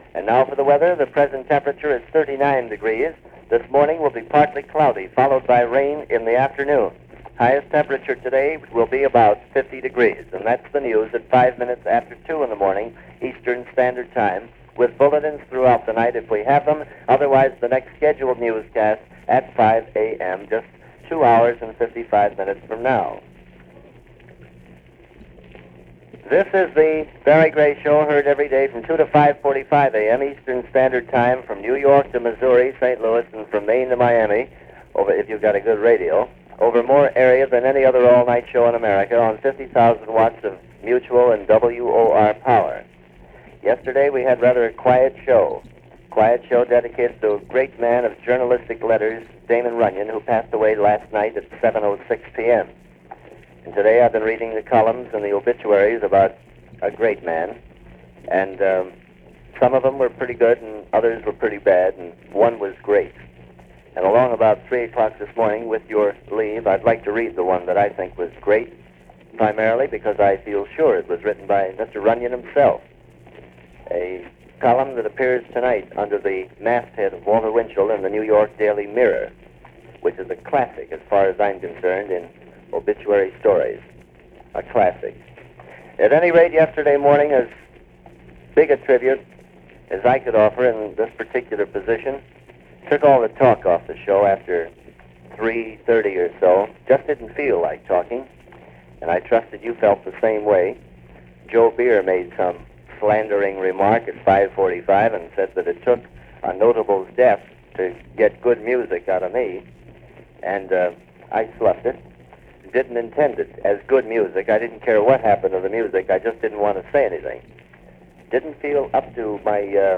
Sounds For A City That Never Sleeps - Barry Gray And New York All Night Radio - 1946 - an excerpt of program featuring the Father of Talk Radio.